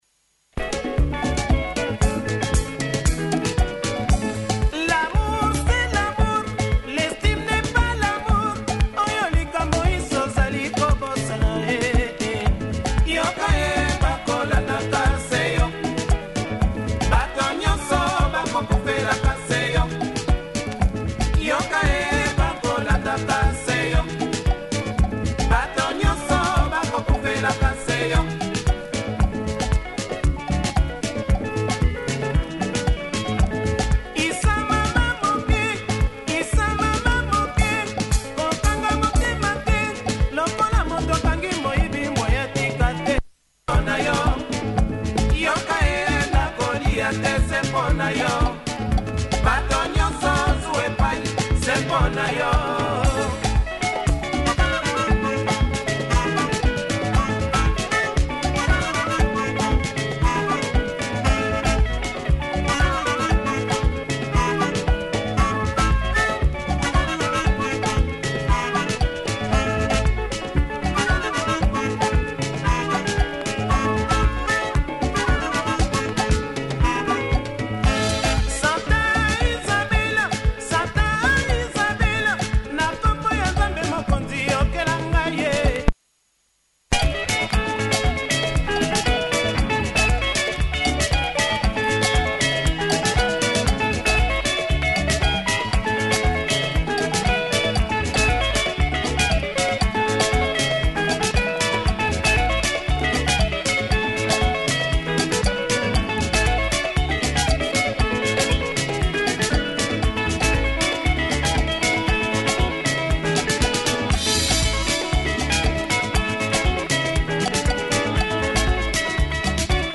Nice catchy lingala track
flute in the horn section!